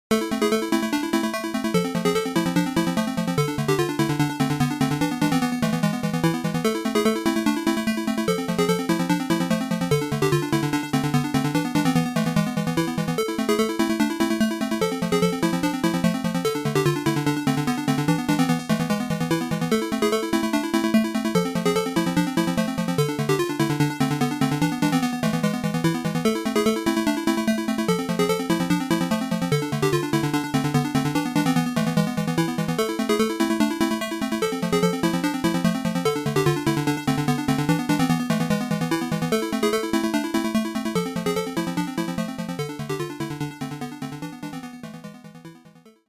ここではMUCOM88winで作ったMUCソースとMP3化したファイルを掲載しています。